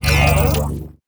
ZombieSkill_SFX
sfx_skill 12_1.wav